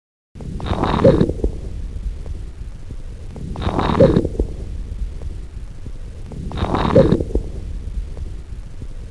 Late-Inspiratory-Squeak.mp3